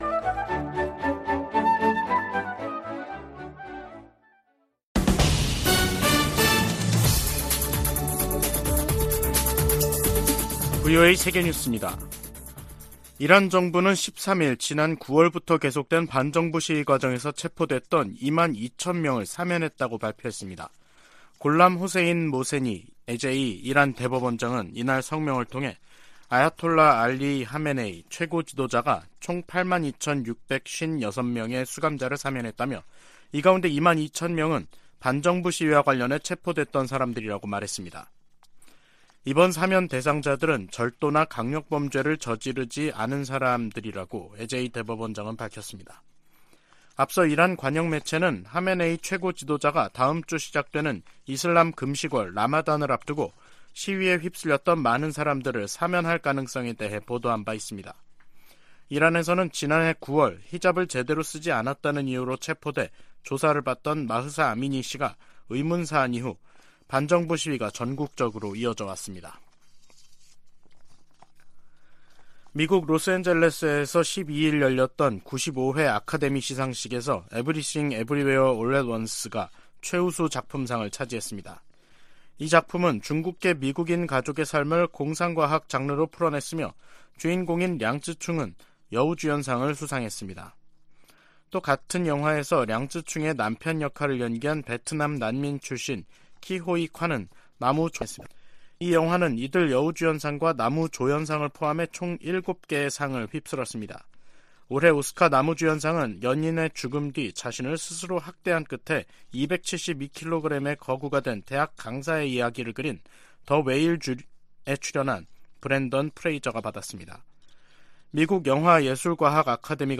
VOA 한국어 간판 뉴스 프로그램 '뉴스 투데이', 2023년 3월 13일 3부 방송입니다. 북한이 12일 전략순항미사일 수중발사훈련을 실시했다고 다음날 대외 관영 매체들이 보도했습니다. 미국과 한국은 ‘자유의 방패’ 연합연습을 시작했습니다. 미 국무부가 북한의 최근 단거리 탄도미사일 발사를 규탄하며 대화 복귀를 촉구했습니다.